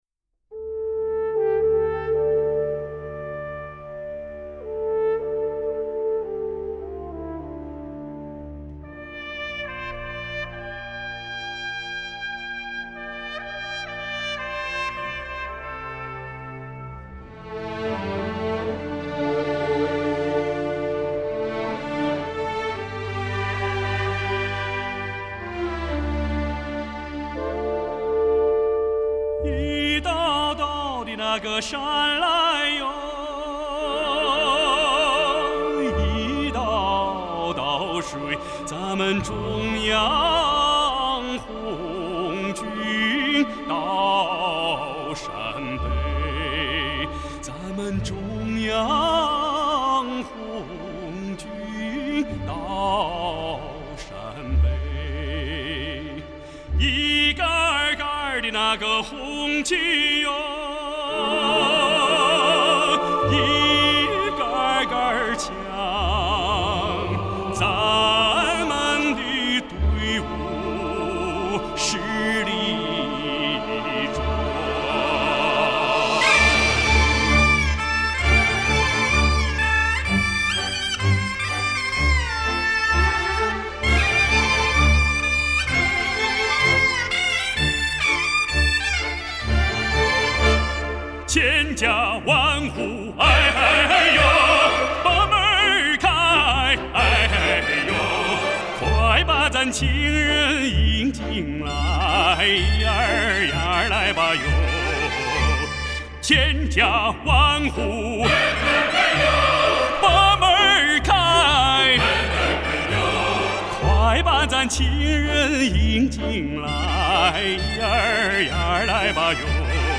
这张最新专辑精心选择了一批各个历史时期流传的军旅歌曲。
运用美声、民族等不同唱法完美演绎了专辑的每一首歌曲。